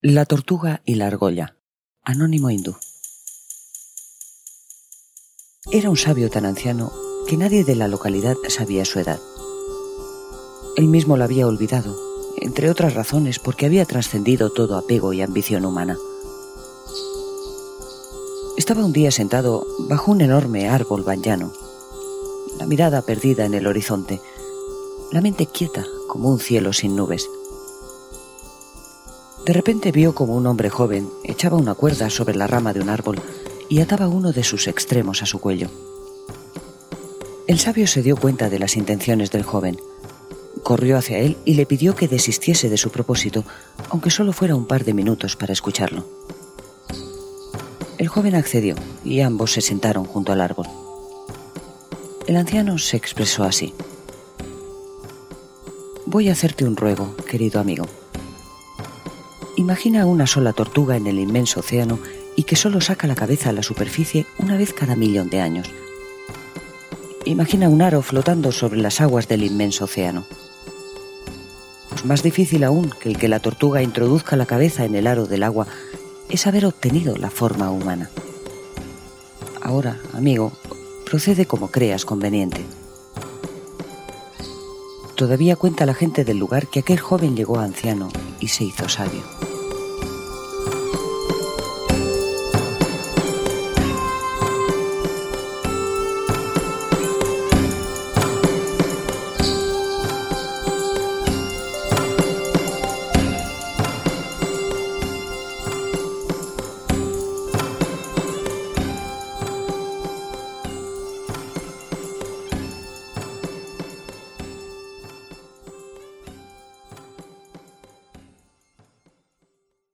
Audiolibro: La tortuga y la arogolla
Cuento oriental